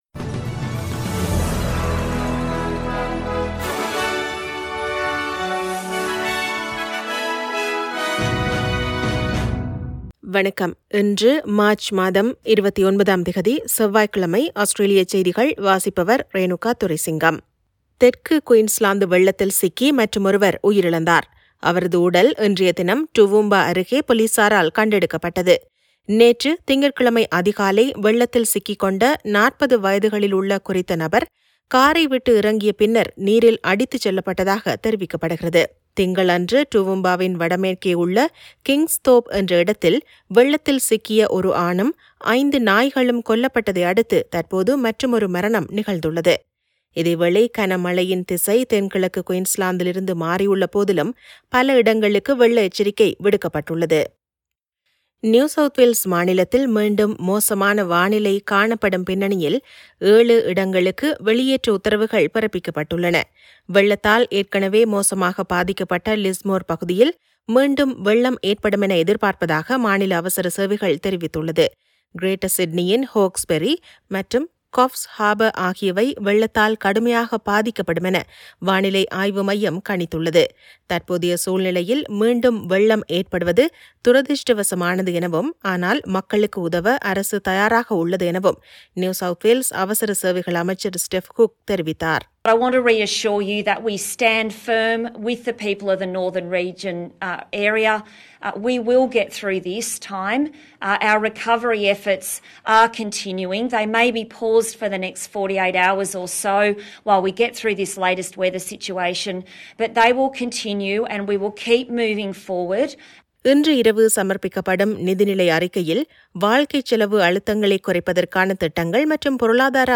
Australian news bulletin for Tuesday 29 March 2022.